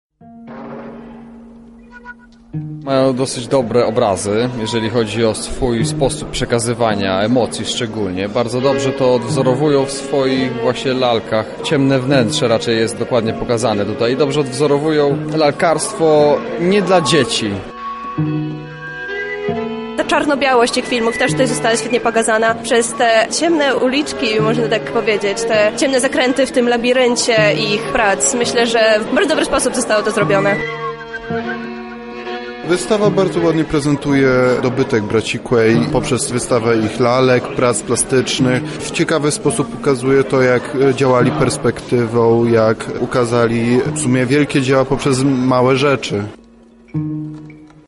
Widzowie zdradzili jak odnaleźli się w tym odzwierciedleniu filmowego świata Thimothy’ego i Stephena Quey’ów: